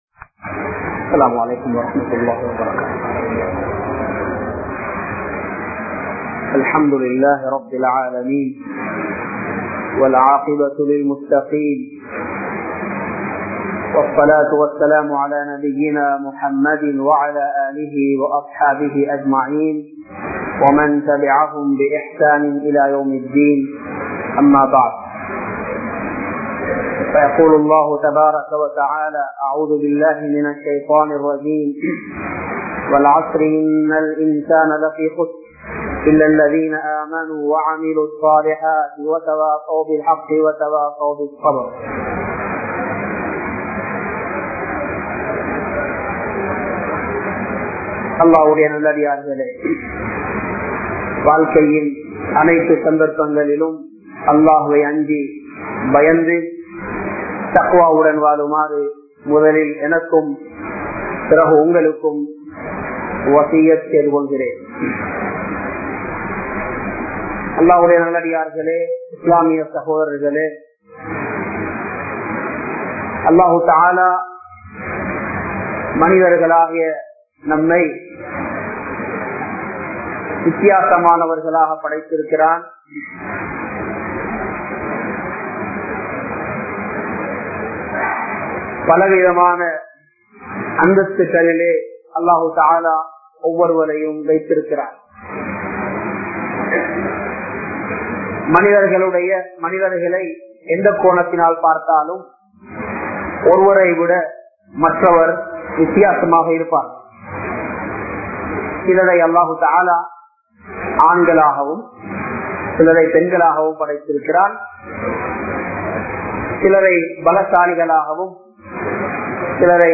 Perumai Enum Noai (பெருமை எனும் நோய்) | Audio Bayans | All Ceylon Muslim Youth Community | Addalaichenai
Muhiyadeen Jumua Masjith